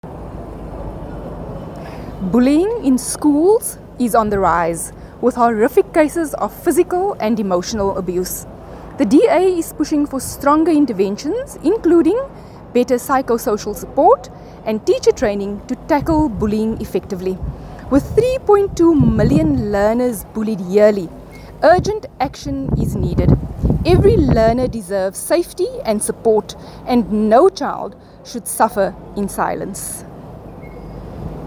soundbite by Dr Delmaine Christians MP.
Dr-Delmaine-Christians-soundbite.mp3